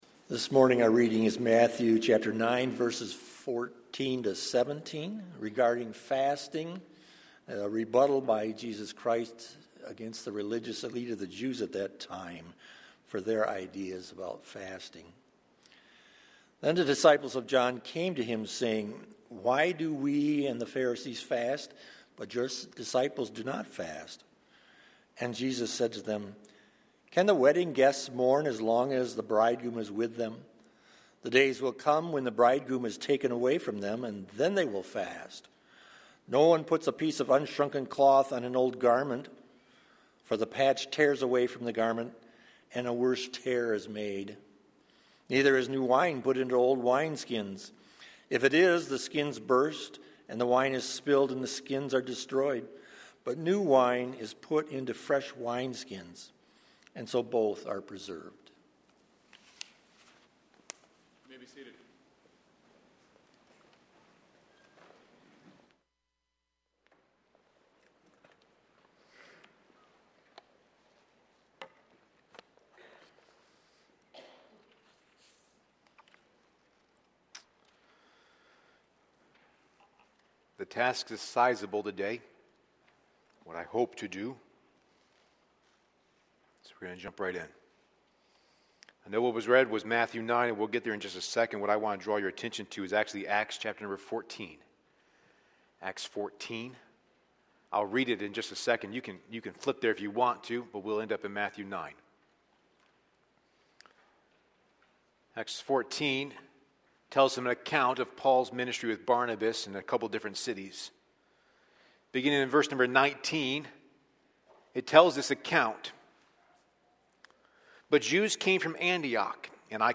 A sermon from the series "Prayer."